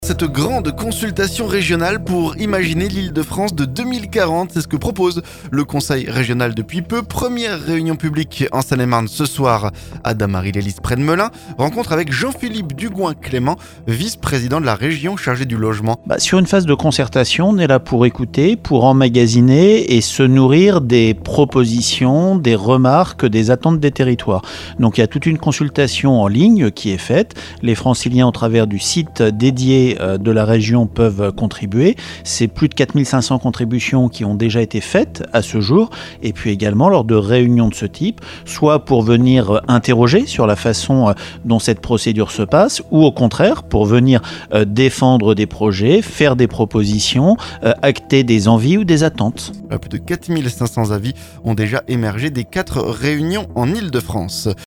Rencontre avec Jean-Philippe Dugoin-Clément, vice-président de la région chargé du Logement.